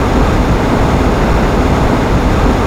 sci-fi_vehicle_spaceship_jet_engine_loop2.wav